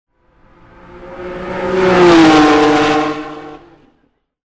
Звуки гонок
Звук автомобиля промчавшегося с бешеной скорости на гонке